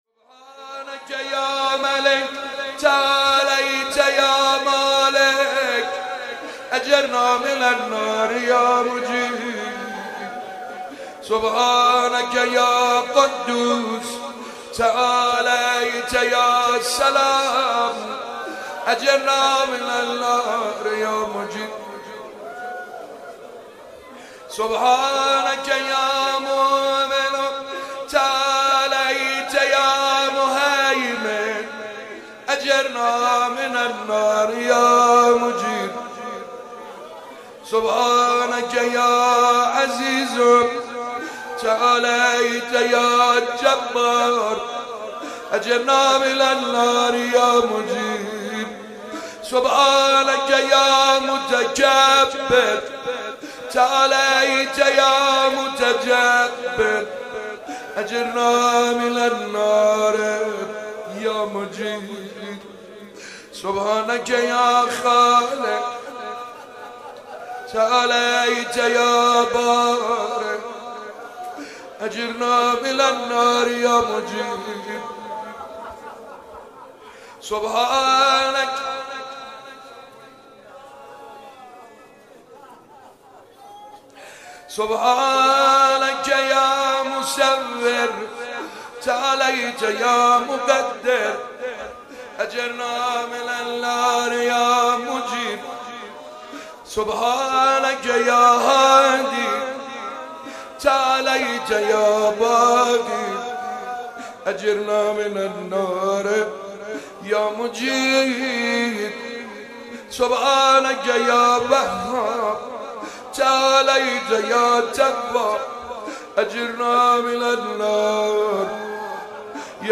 شب 13 ماه مبارک رمضان 95_دعا خوانی